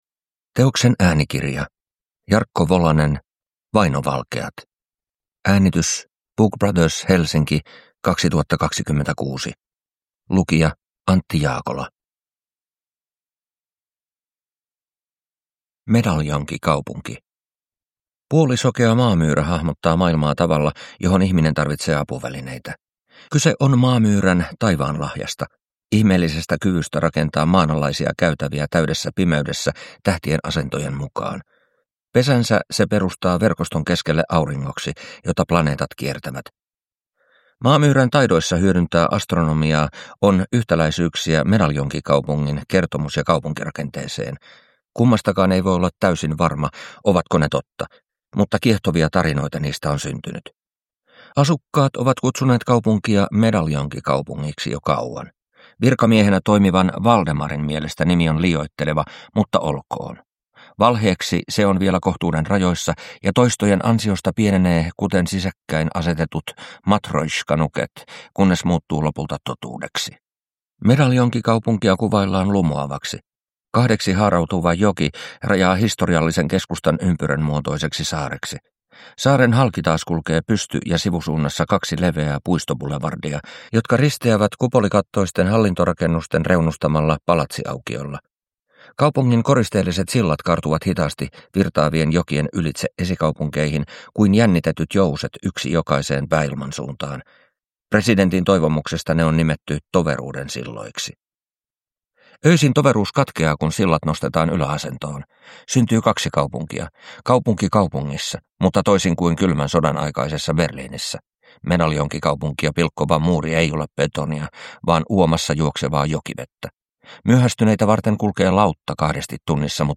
Vainovalkeat – Ljudbok